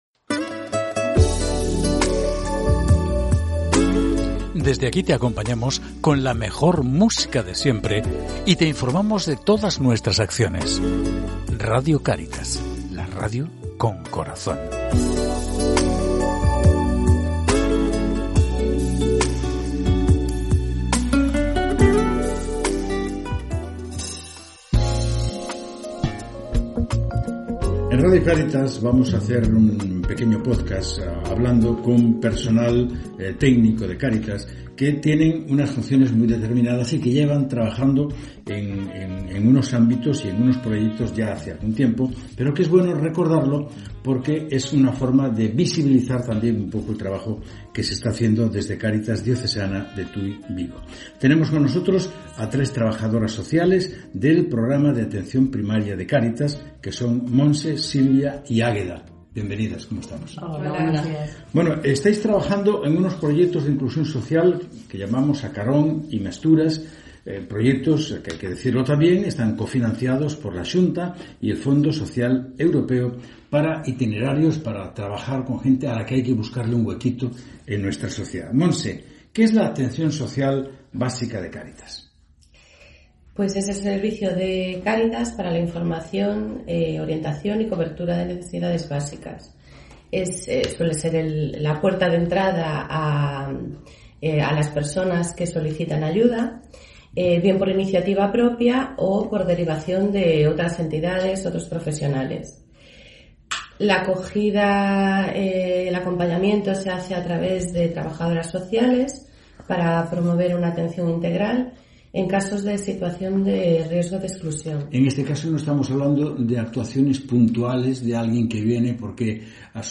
Entrevista-Atencion-Primaria-comprimida.mp3